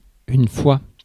Ääntäminen
IPA : /əˈkeɪ.ʒən/